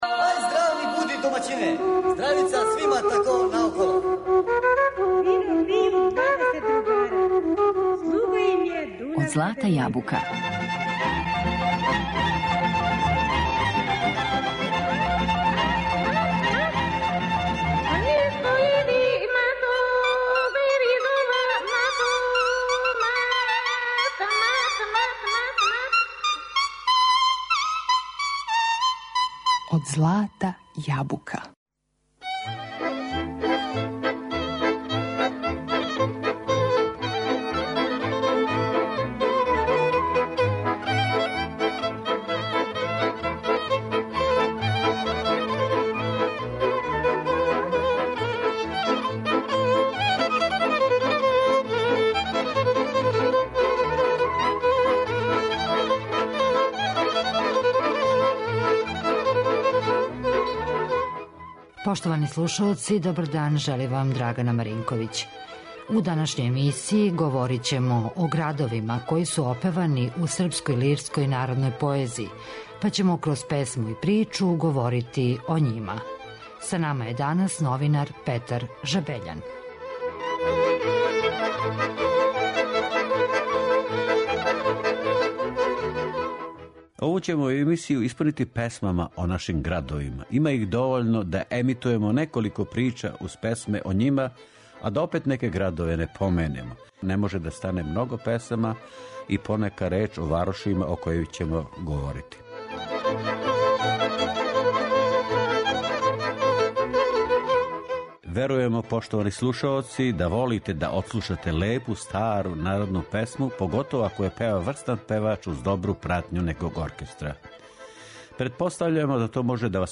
Кроз песму и причу, данас ћемо проћи кроз Београд, Смедерево, Једрен, Кикинду, Нови Сад...